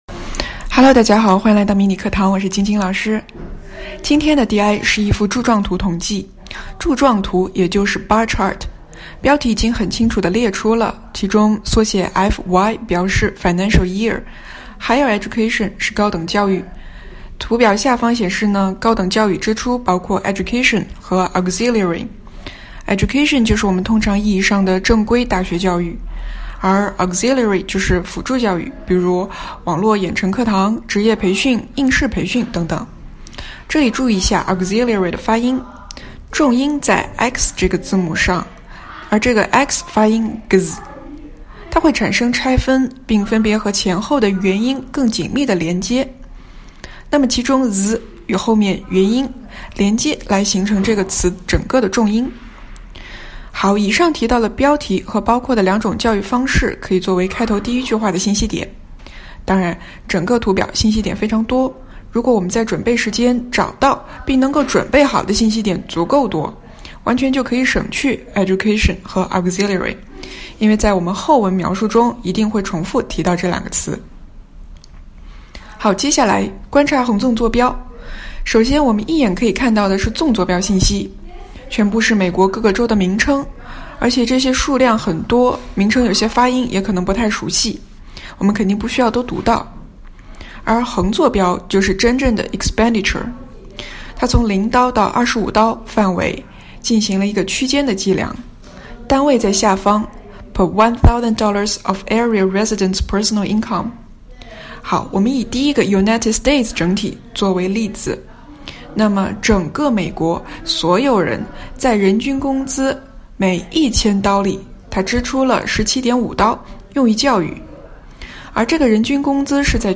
第二步，听老师讲解，比较出自己描述、发音、语调、断句等有问题的地方
DI-高等教育费用老师讲解示范.mp3